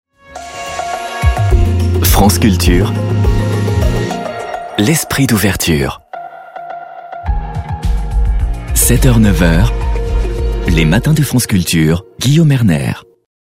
voix off habillage
3. FRANCE CULTURE habillage